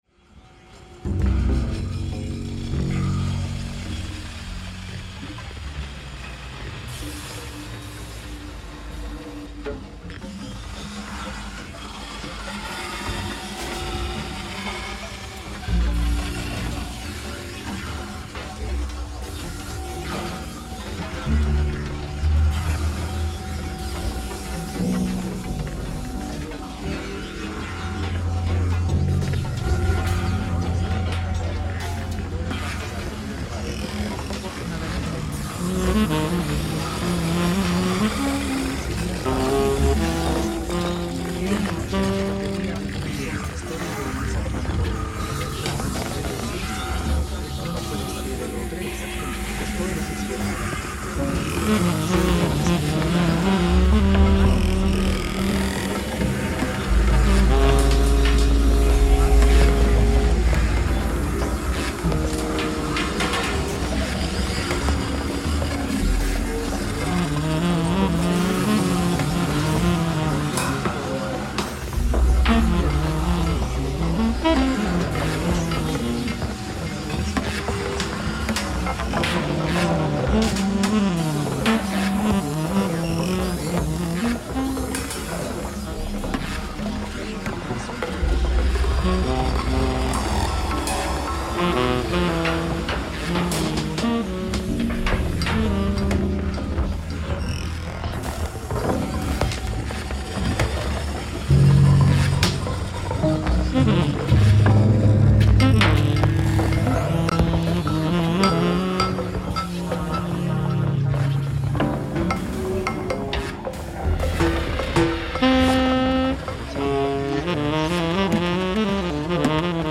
live streamed on 16 January 2026
Saxophone and Ladder
(Live mashed) sonic fictions